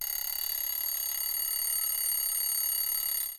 alarm-clock.wav